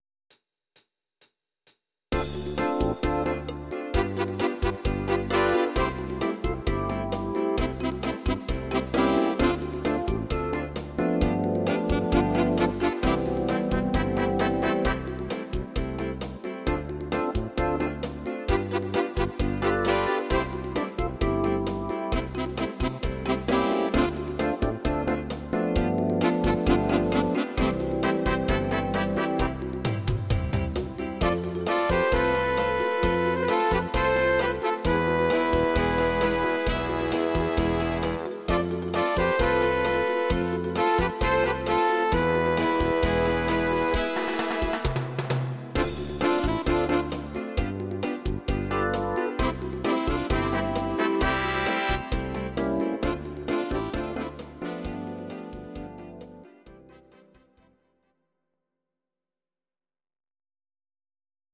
instr. Combo